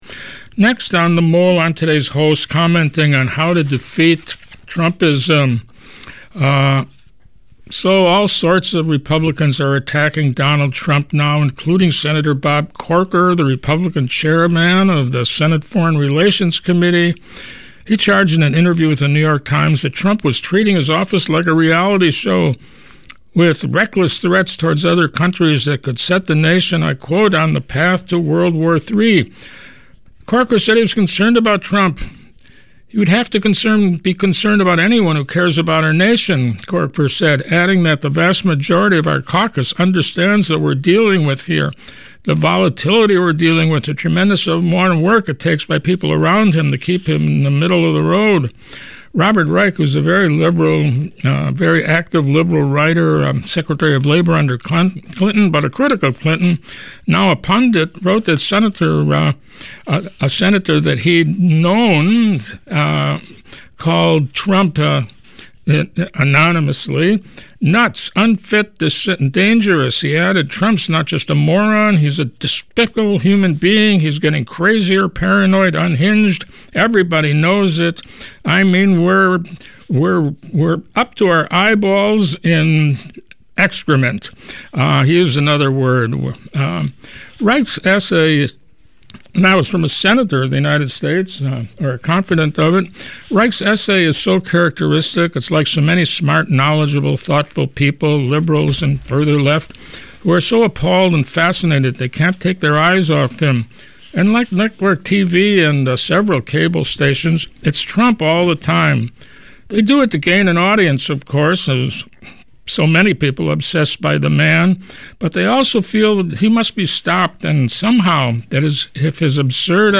trumpism_commentary.mp3